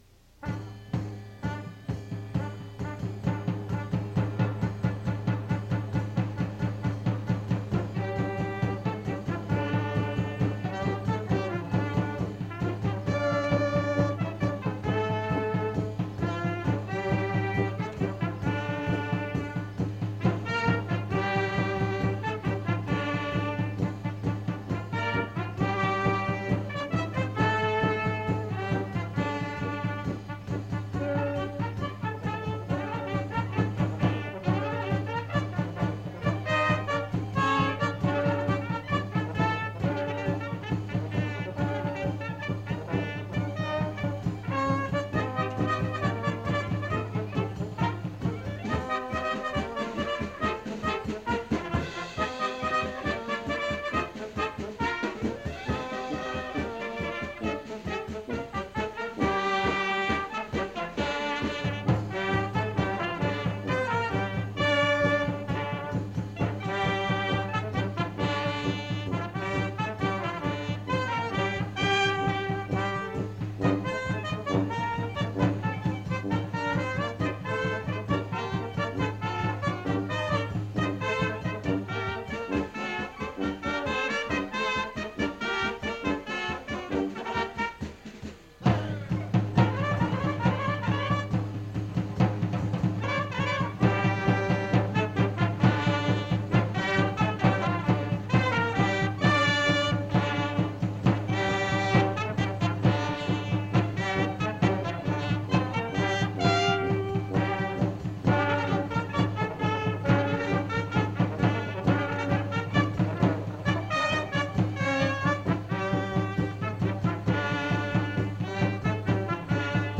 Now you can listen to any or all of the playlist from that first WJU Convention in Key Biscayne, Florida in January,1973.